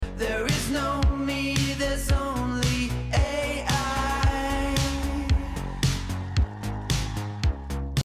80s New Wave.